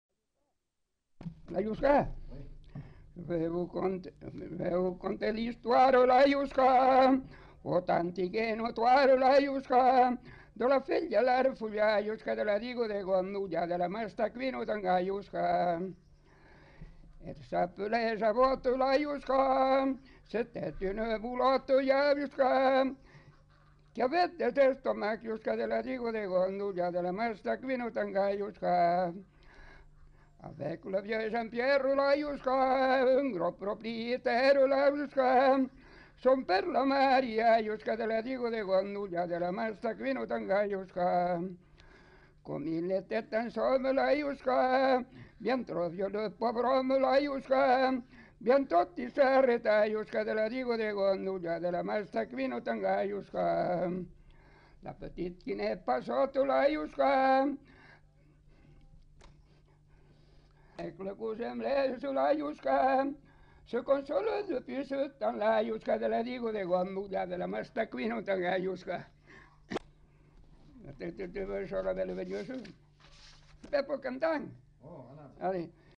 Lieu : [sans lieu] ; Gers
Genre : chant
Effectif : 1
Type de voix : voix d'homme
Production du son : chanté
Danse : youska